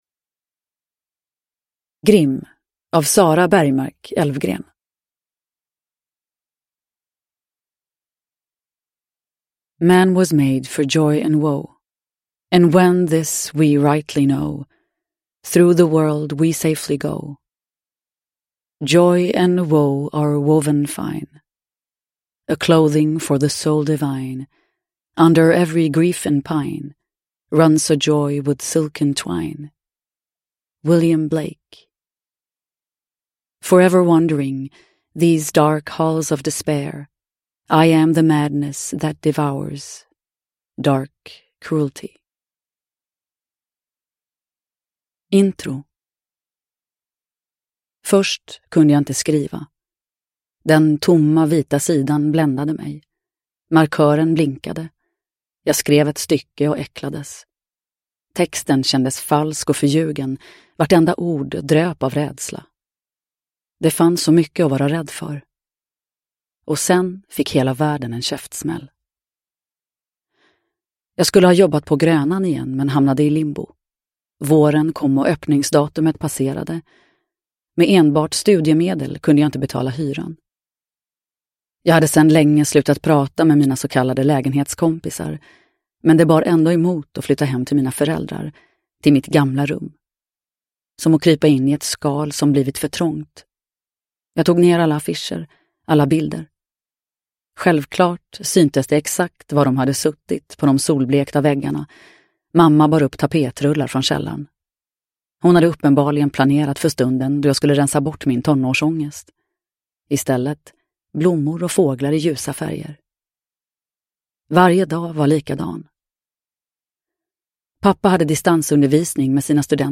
Uppläsare: Nina Zanjani